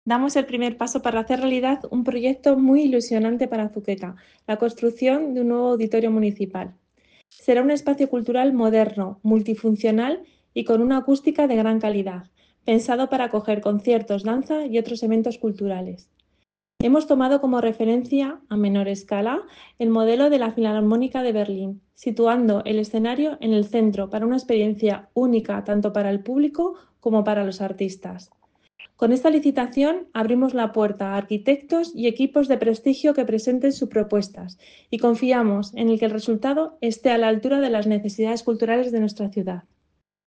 Declaraciones de la concejala de Urbanismo, Yolanda Rodríguez